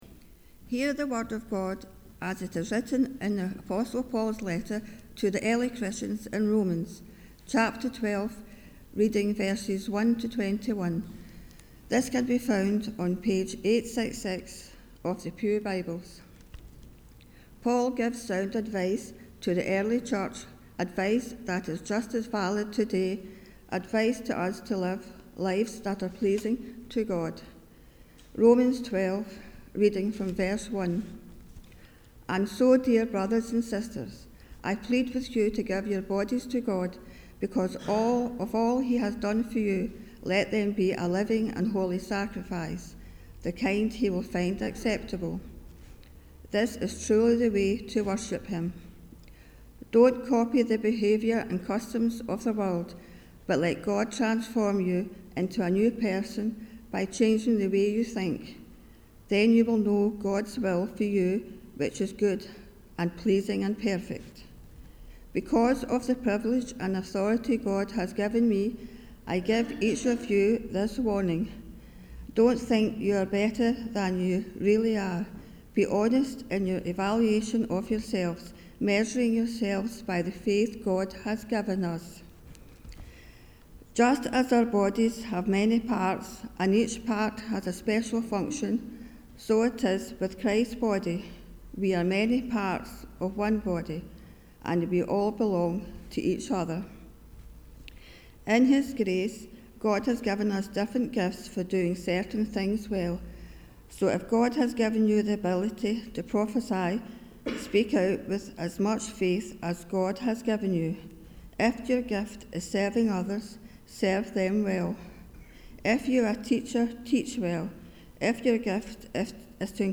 The Scripture Readings prior to the Sermon is Romans 12: 1-21